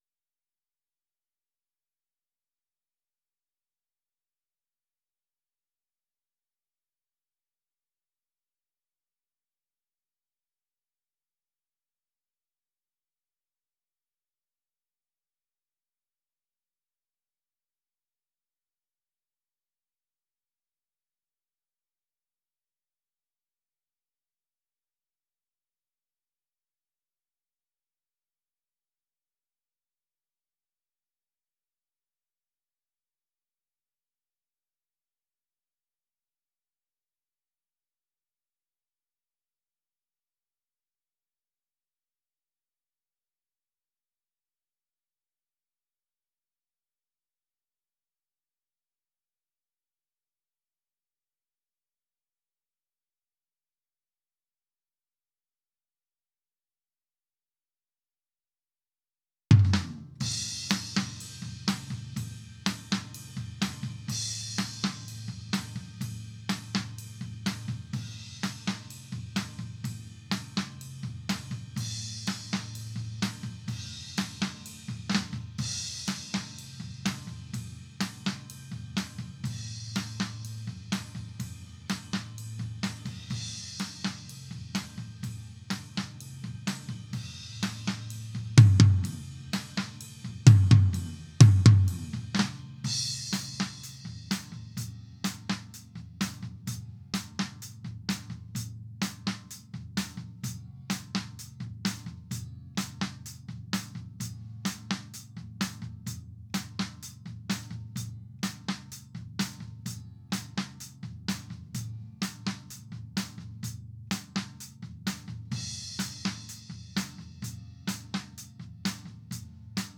White Racktom.wav